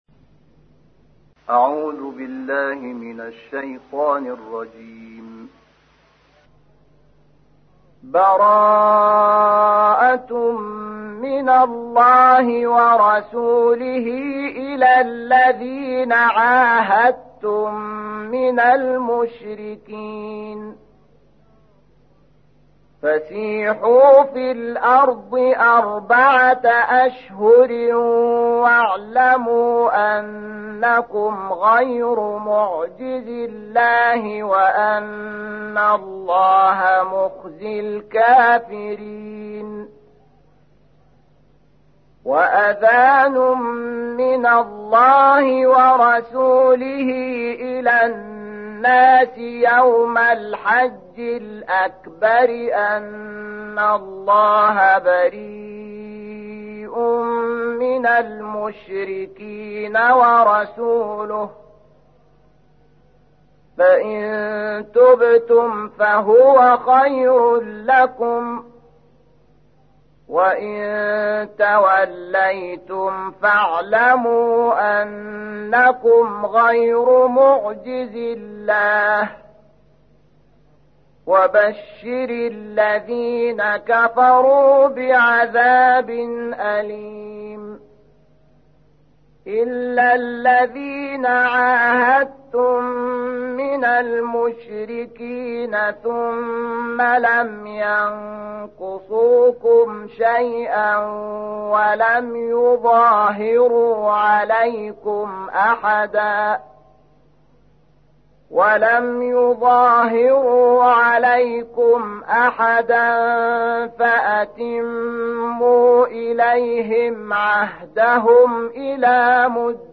تحميل : 9. سورة التوبة / القارئ شحات محمد انور / القرآن الكريم / موقع يا حسين